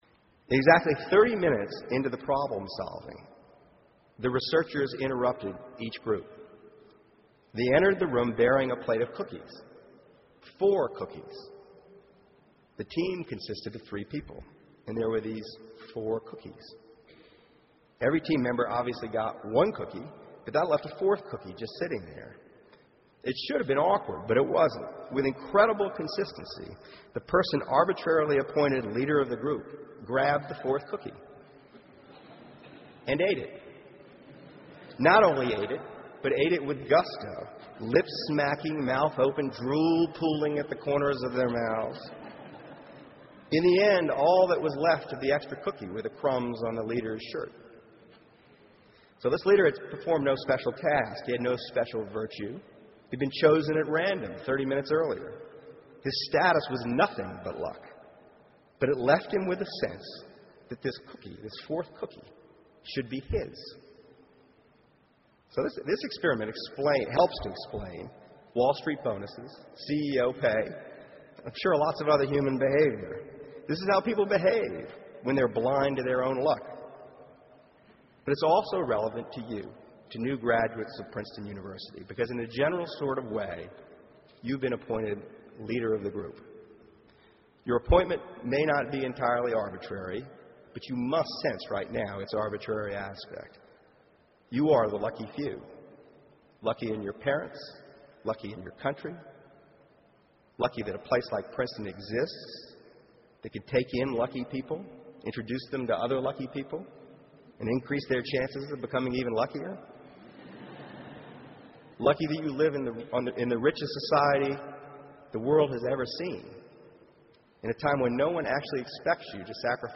公众人物毕业演讲 第143期:2012年Michael Lewis普林斯顿大学(7) 听力文件下载—在线英语听力室